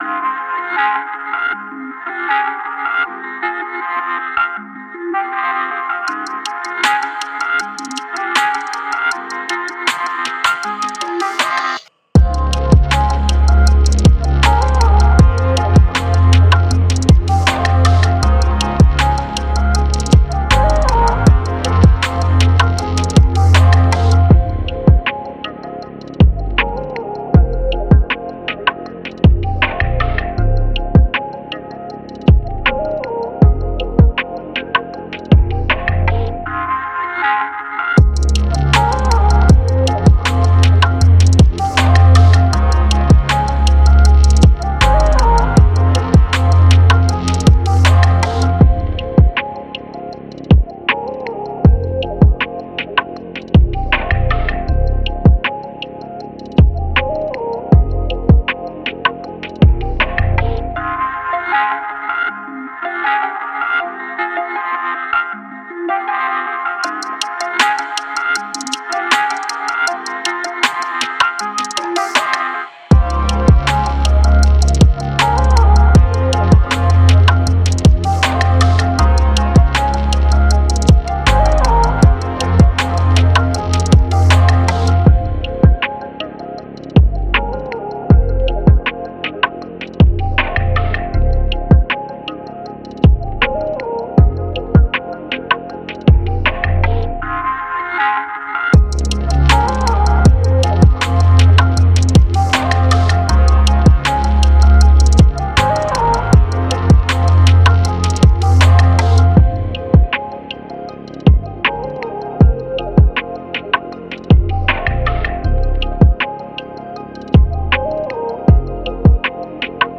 Музыка для тренировок